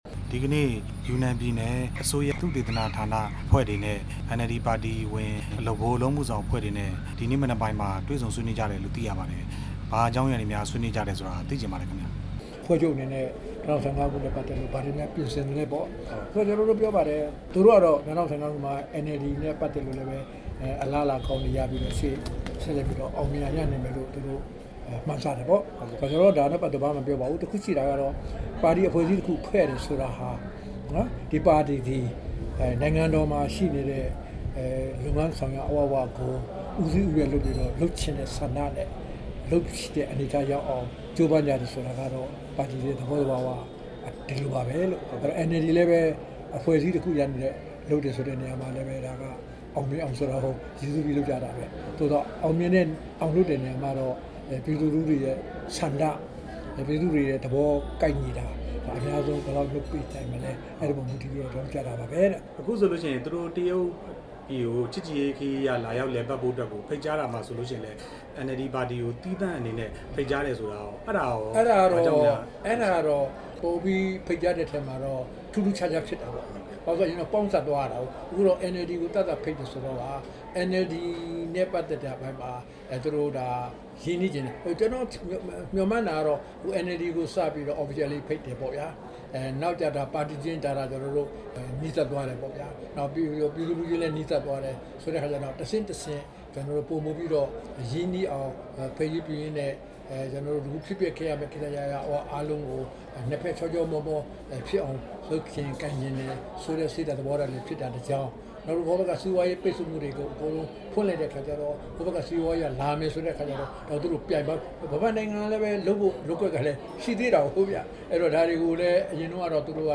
ဦးတင်ဦးနဲ့ တွေ့ဆုံ မေးမြန်းချက်